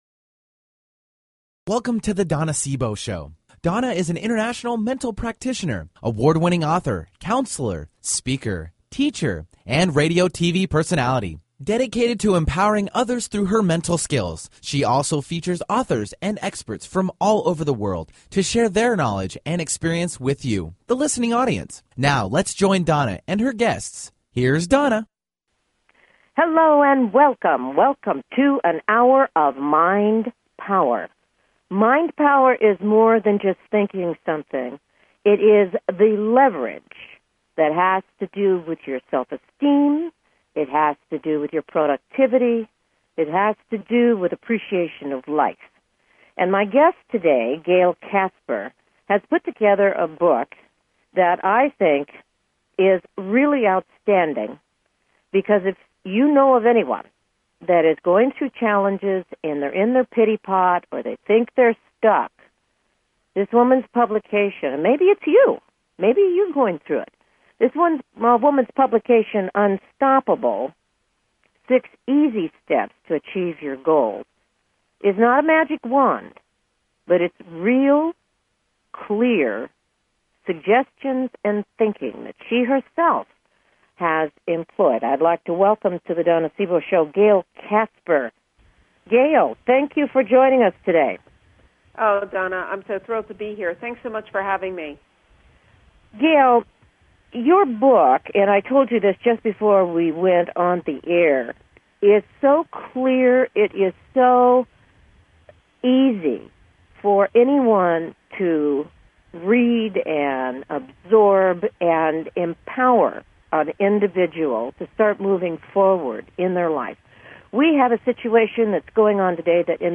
Talk Show Episode, Audio Podcast
Guests on her programs include CEO's of Fortune 500 companies to working mothers.
Tune in for an "Hour of Mind Power". Callers are welcome to call in for a live on air psychic reading during the second half hour of each show.